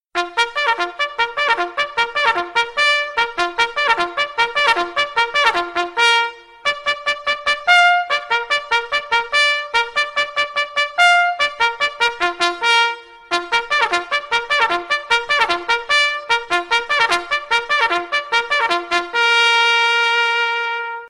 Эти треки воспроизводят настоящие горны и трубы, используемые для утреннего подъёма солдат.
Звук трубы, возвещающий подъем